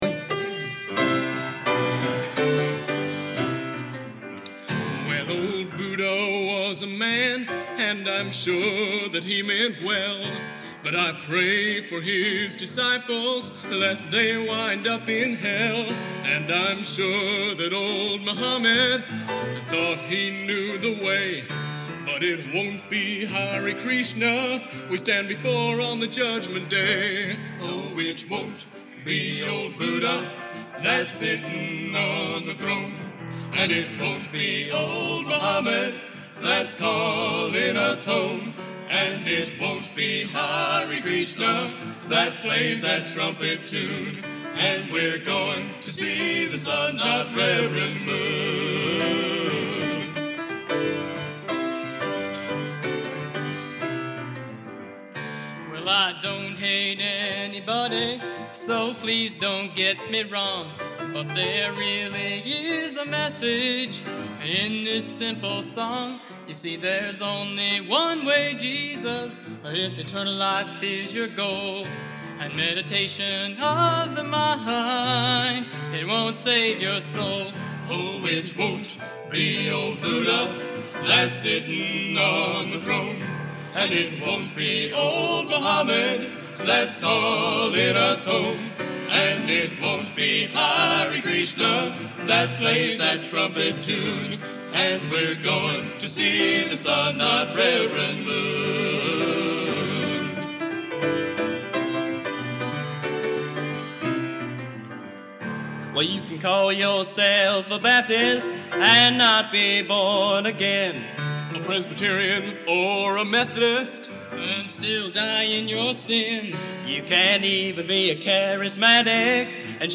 Genre: Gospel | Type: